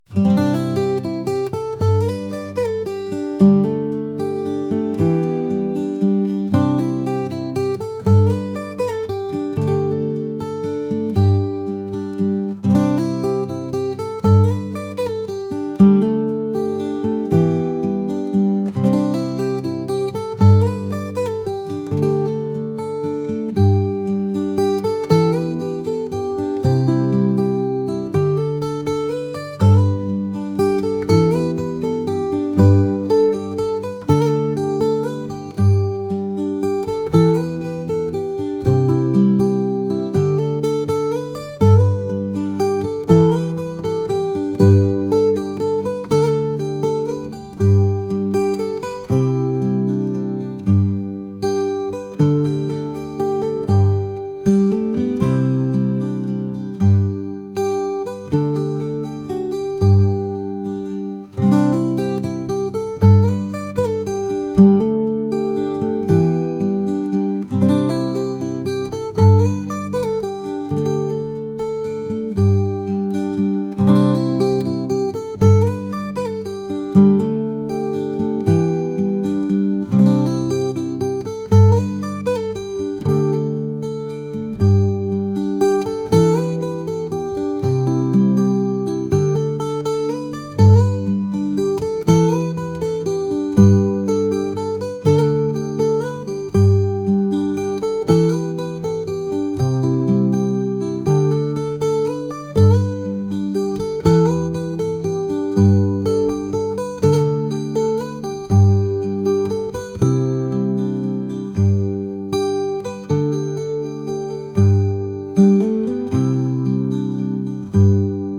acoustic | folk | indie